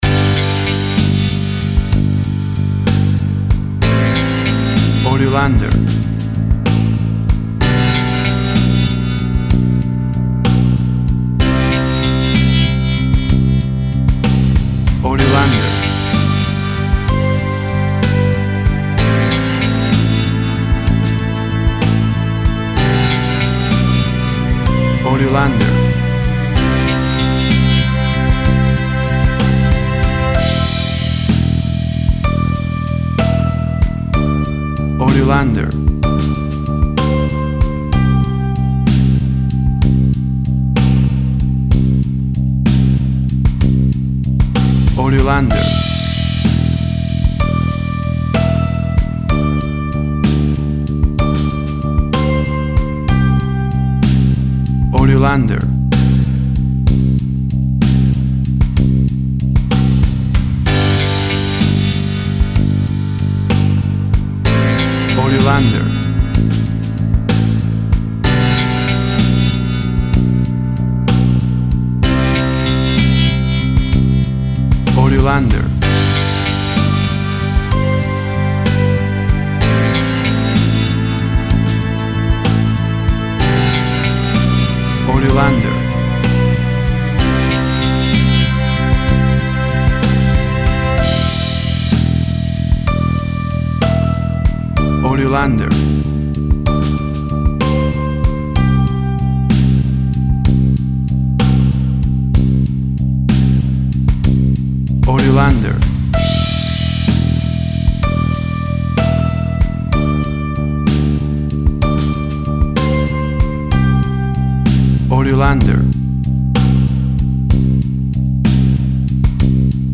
WAV Sample Rate 16-Bit Stereo, 44.1 kHz
Tempo (BPM) 63